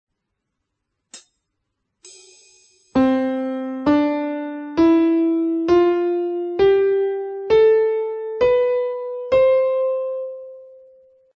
scala_maggiore_(63,kb.mp3